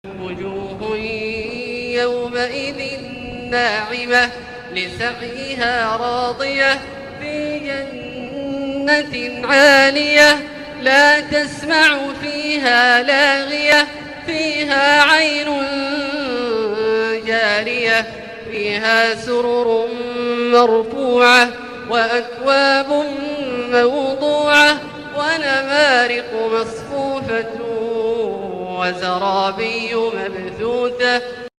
أوصاف الجنة من سورة الغاشية بترتيل مبهر للشيخ عبدالله الجهني > التلاوات المترجمة > المزيد - تلاوات عبدالله الجهني